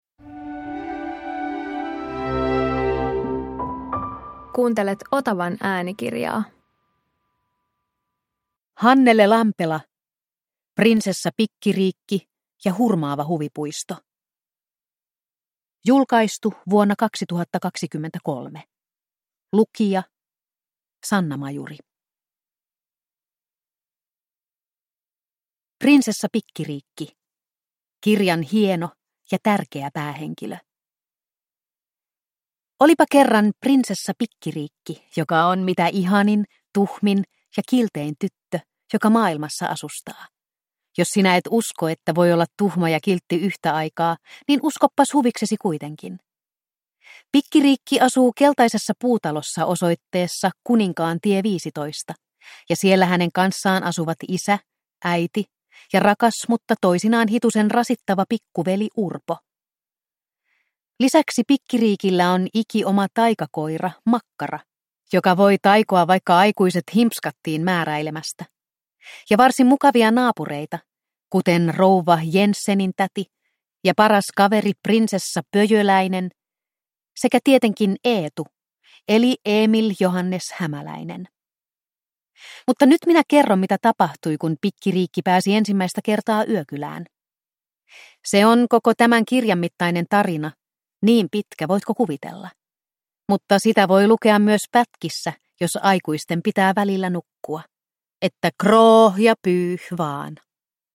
Prinsessa Pikkiriikki ja hurmaava huvipuisto – Ljudbok – Laddas ner